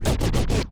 scratch06.wav